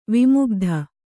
♪ vimugdha